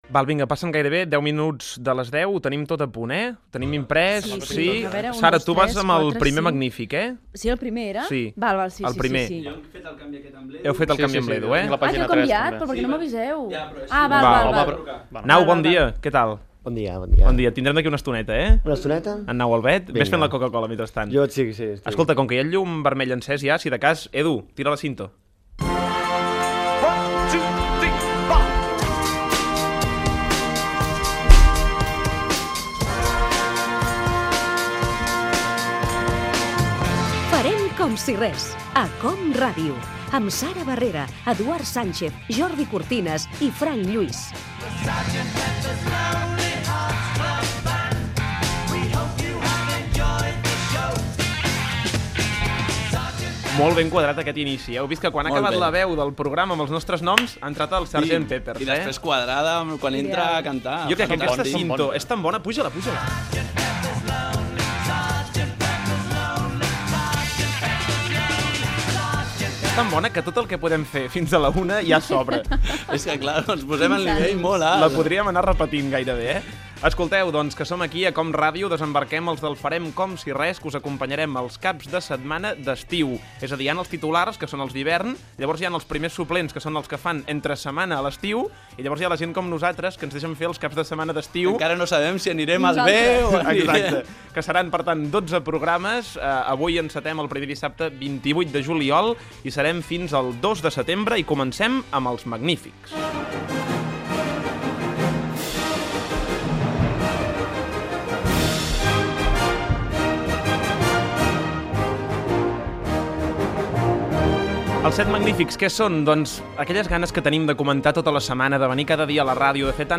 Inici del programa. Careta amb el crèdits, comentari de la programació estiuenca, "Els set magnífics"
Entreteniment
FM